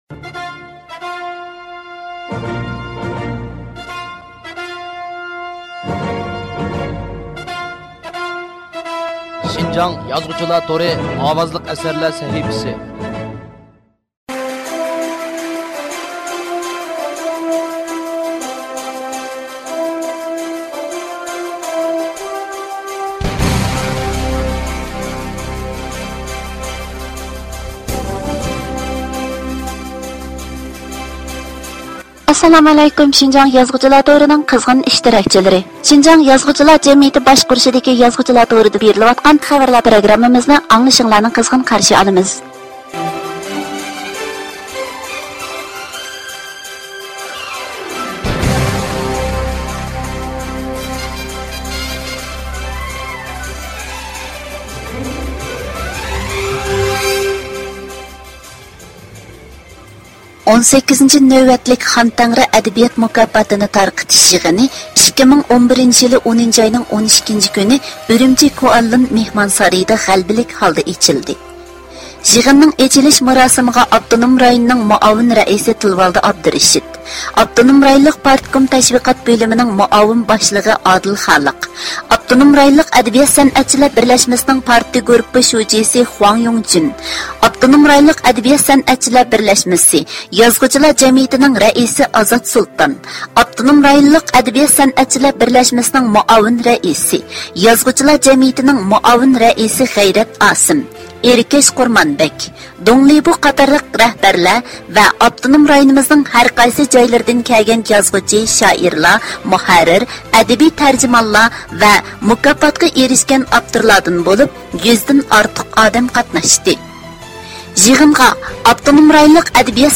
ئاۋازلىق: ئەدەبىي خەۋەرلەر پروگراممىسى: 1-سان (سىناق نۇسخا)